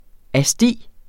Udtale [ aˈsdi ]